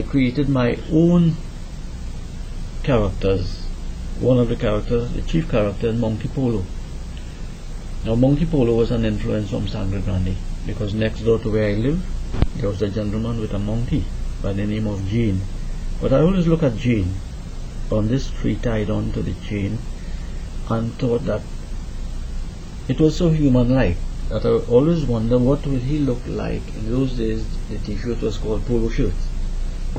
1 audio cassette
Kheeshas, Folk Characters, Storytelling, Indian Folk-tales, Cultural Heritage: Trinidad and Tobago, Folklore